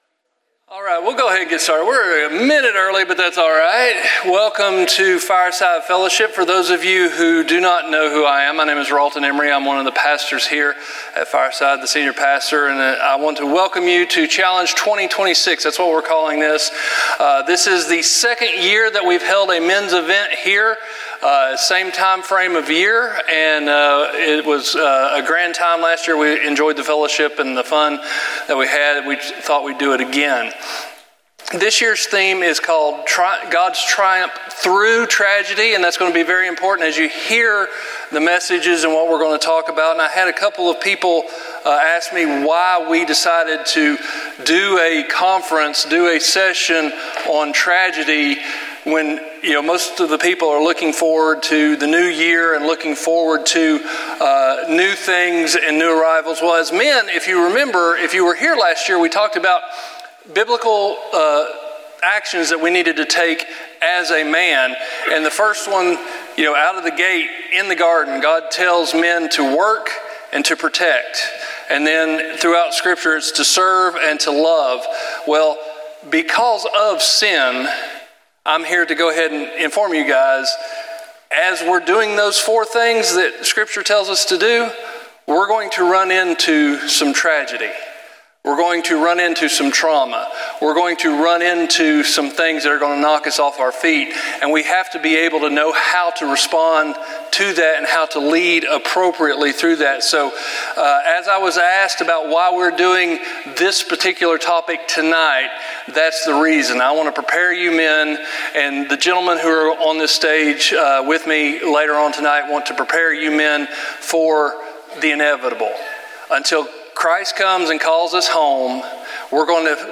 Session 1 of our Men's Conference from December 2025.